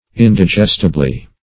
indigestibly - definition of indigestibly - synonyms, pronunciation, spelling from Free Dictionary
In`di*gest"i*ble*ness, n. -- In`di*gest"i*bly, adv.